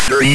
A Go application to generate numbers station like audio output